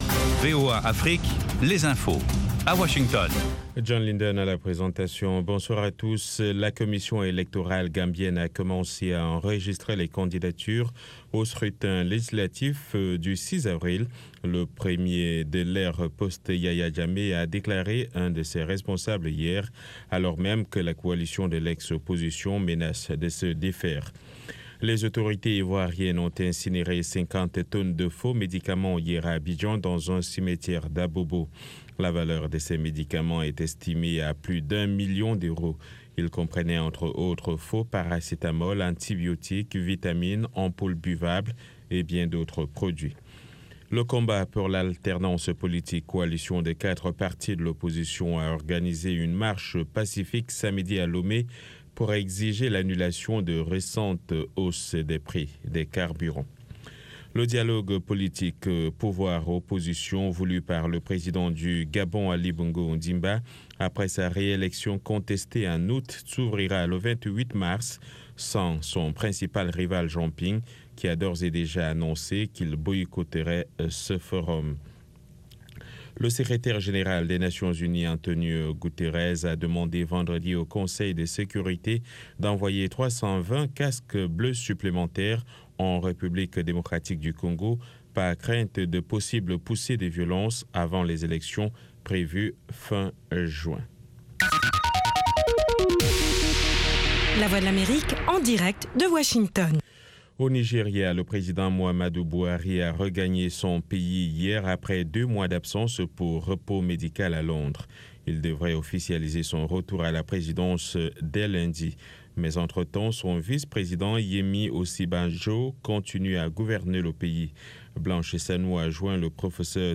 RM Show - R&B et Rock